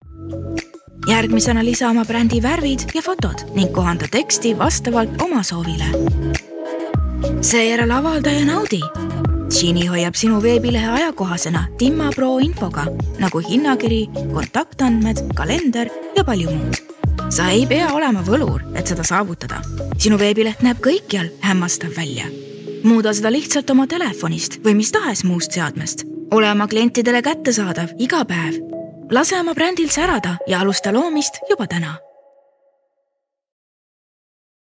Promos
Conversational, young, mature, raspy, seductive, condescending, friendly, cool, warm, softspoken, calm, soothing, motherly, whispery, breathy, monotone, dramatic, funny, mysterious, emotional, youthful, low, genuine, authentic, neutral, intense.